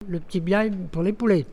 Elle provient de Saint-Urbain.
Locution ( parler, expression, langue,... )